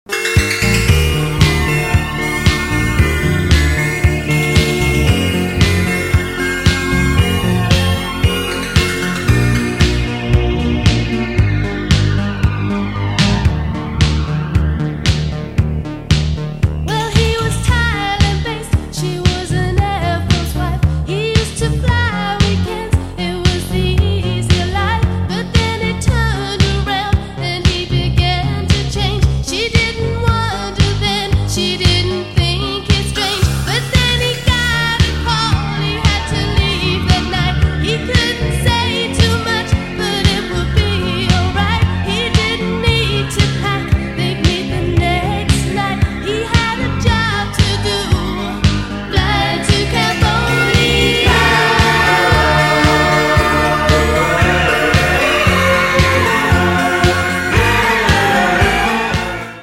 80s vibes